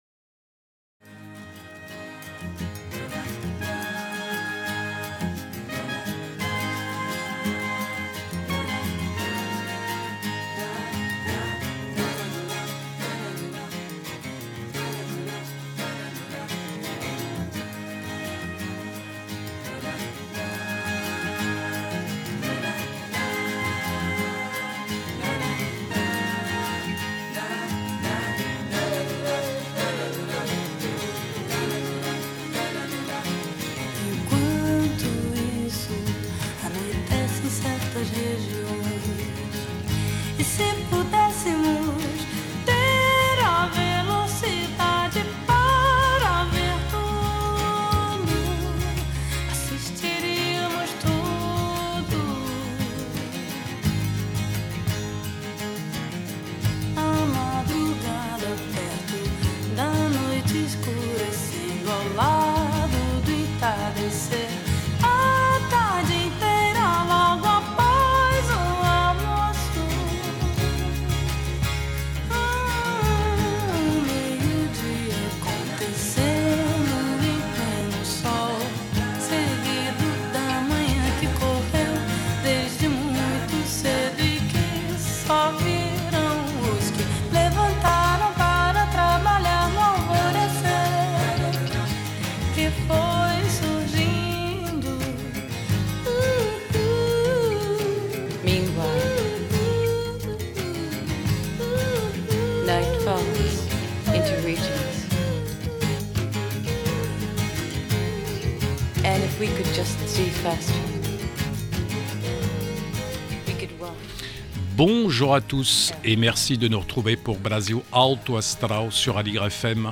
percussionniste